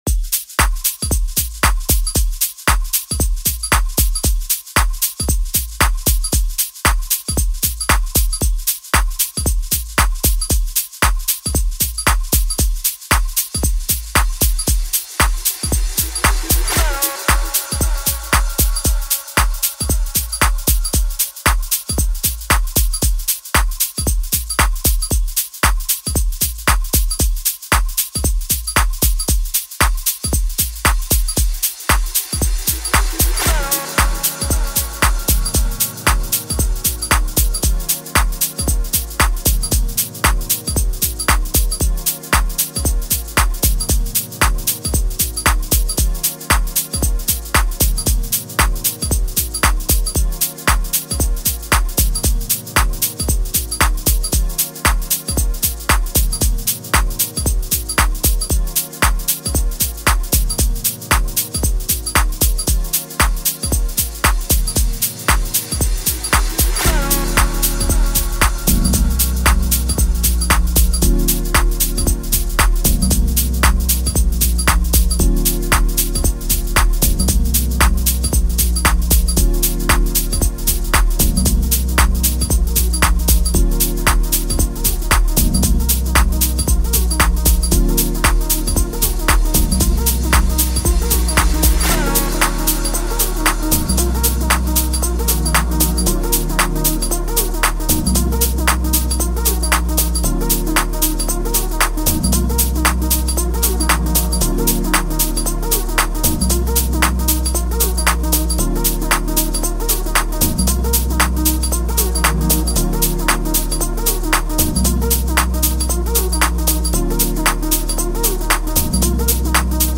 South African singer
afro beat